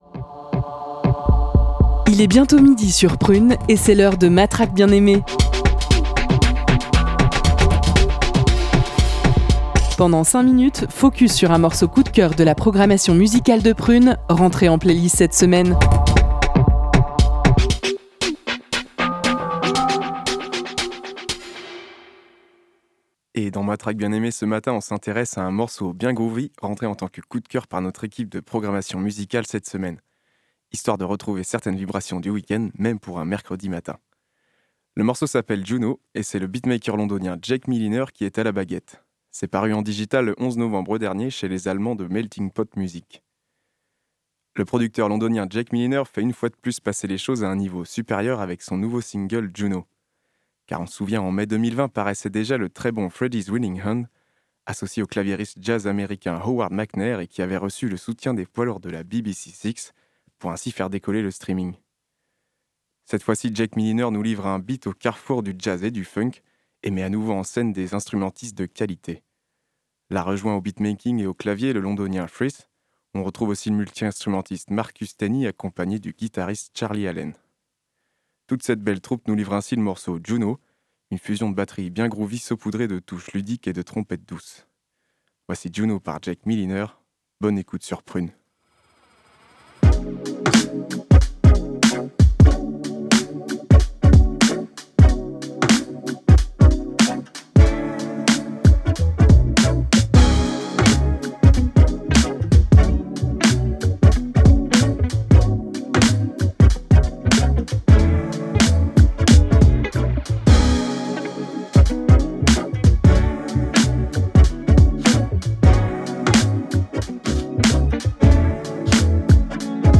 on s’intéresse à un morceau bien groovy